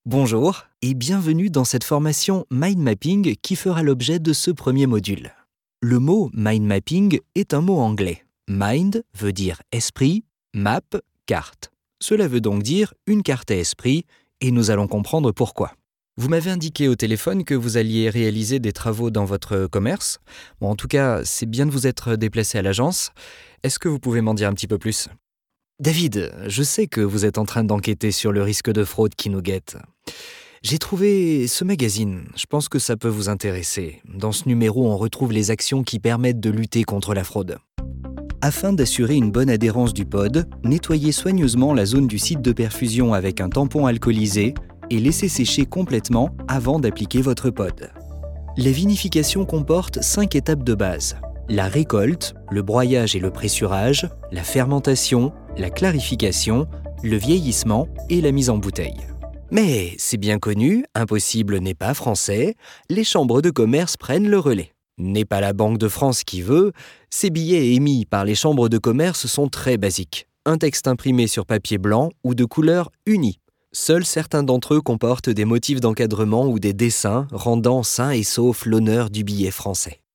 E-learning
Voz versátil de joven adulto a mediana edad, adaptada para dinamizar contenidos multimedia, corporativos y de marca, o para dar un tono serio pero actual a tus narraciones.
NaturalDinámicaOptimista